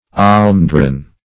Almendron \Al`men*dron"\, n.